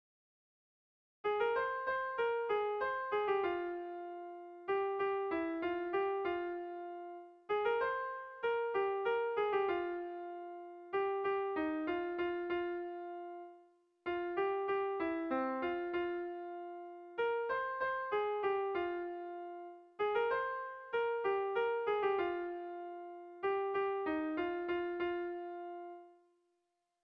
Air de bertsos - Voir fiche   Pour savoir plus sur cette section
Zortziko txikia (hg) / Lau puntuko txikia (ip)
AABA